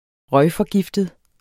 Udtale [ ˈʁʌjfʌˌgifdəð ]